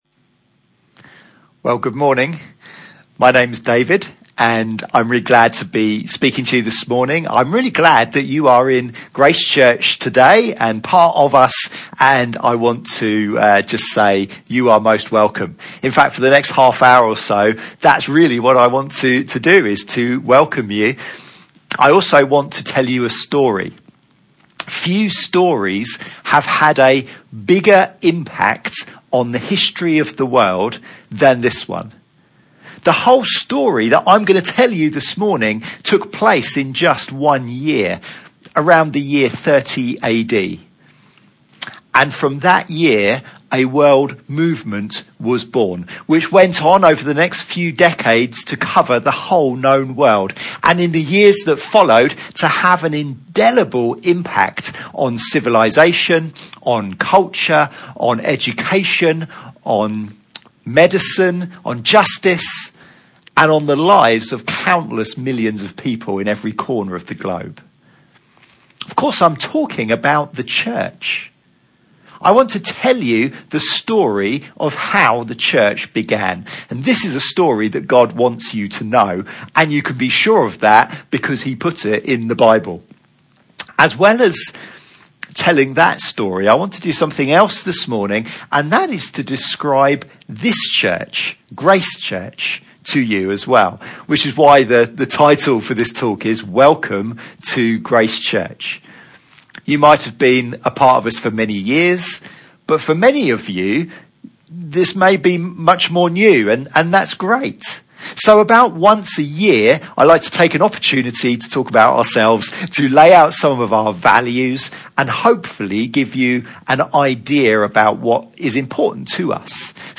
Series: Miscellaneous Sermons 2018